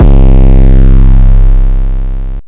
808 - SALUTE.wav